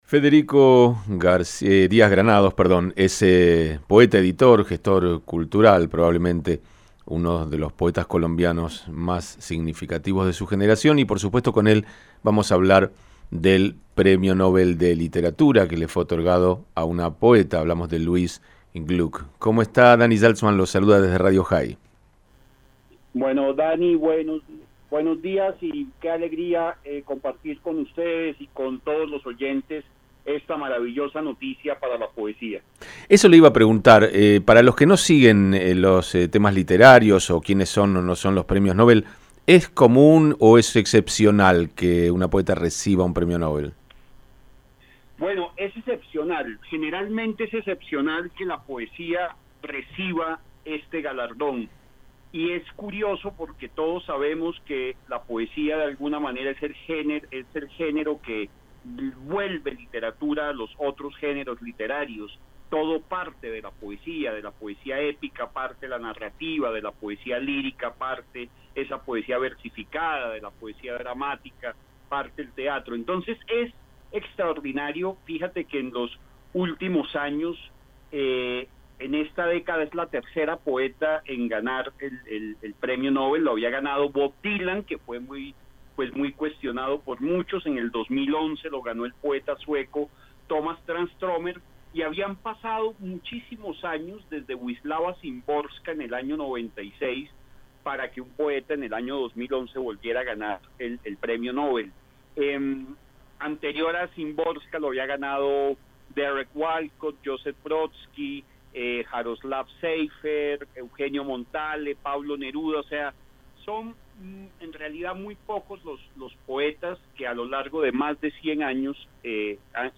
Con él conversamos en Radio Jai sobre el Premio Nobel de Literatura con el que fue galardonada la poeta estadounidense Louise Glück.